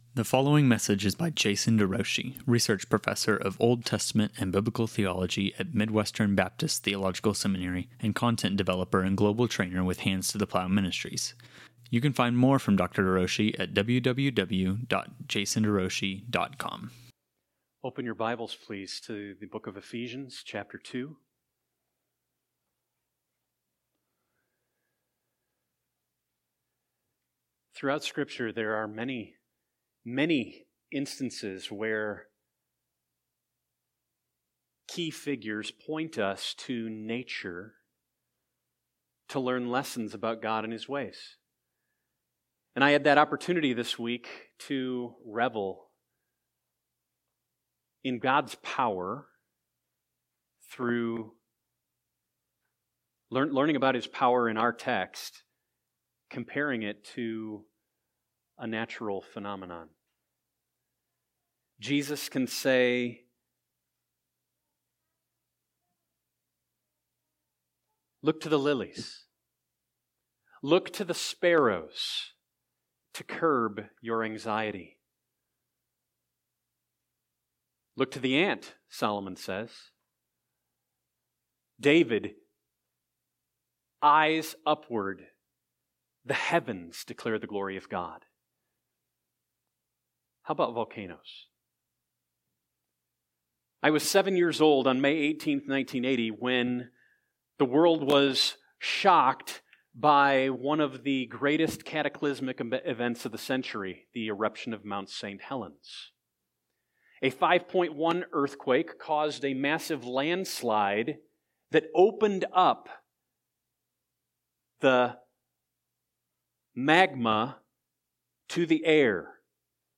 The Christians’ Exalted Life: A Sermon on Ephesians 2:4–7
Eph-24.7-Sermon-.mp3